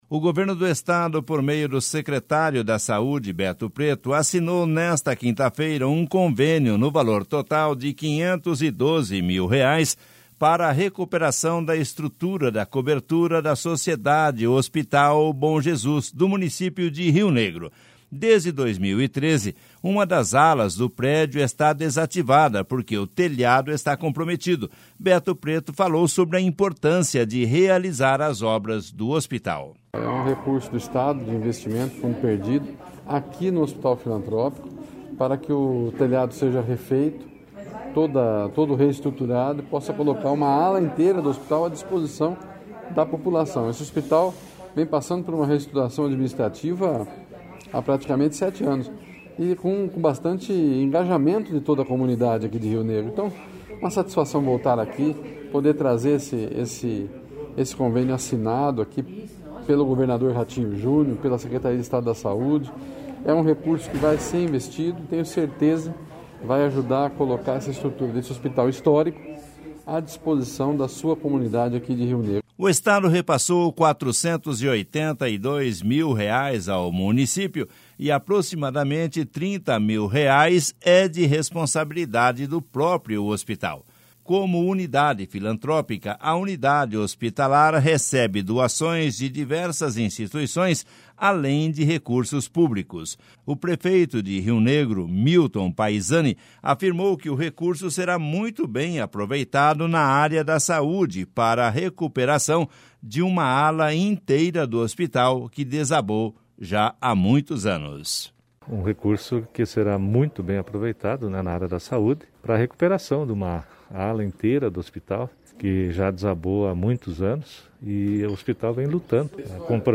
Beto Preto falou sobre a importância de realizar as obras do hospital.// SONORA BETO PRETO.//
O prefeito de Rio Negro, Milton Paizani, afirmou que o recurso será muito bem aproveitado na área da saúde para a recuperação de uma ala inteira do hospital que desabou já há muitos anos.// SONORA MILTON PAIZANI.//